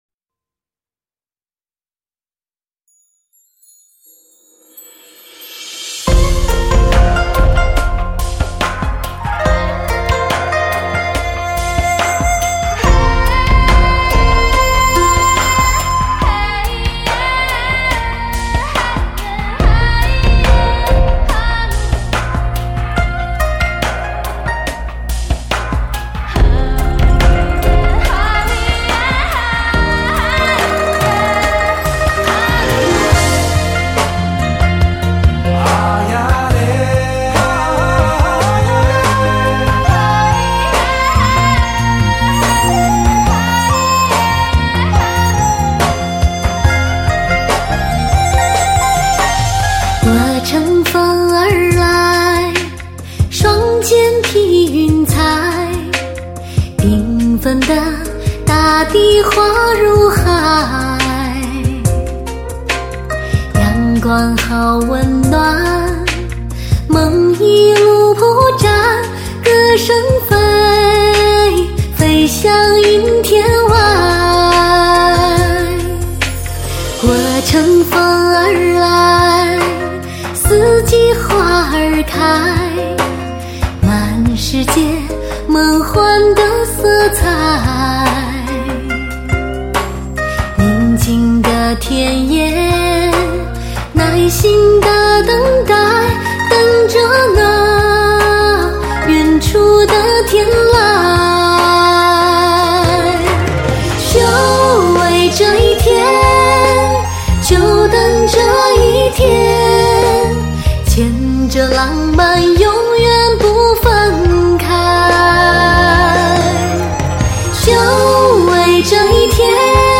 首张集唐古拉音乐风格之精华，最震撼心灵的高原音乐旋律
来自天堂的声音 来自神秘的雪域高原风情 让你流连忘返如痴如醉